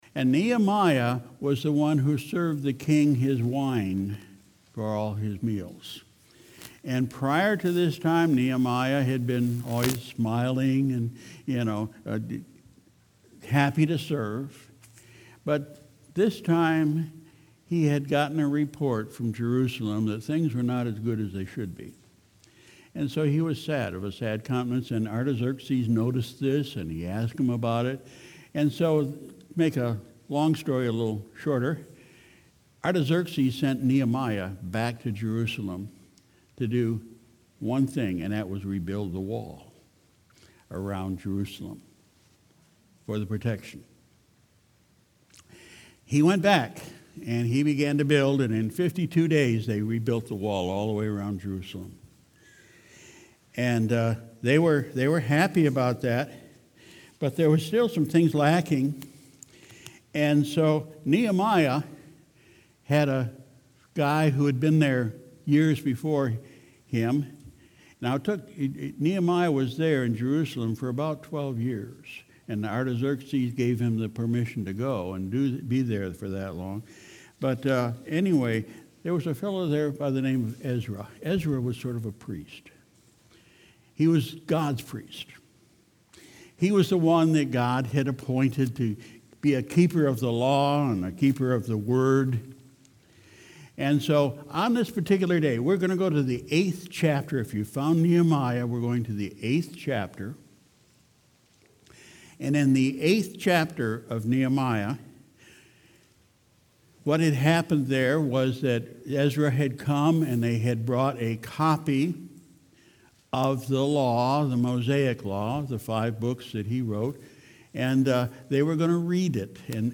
Sunday, August 18, 2019 – Morning Service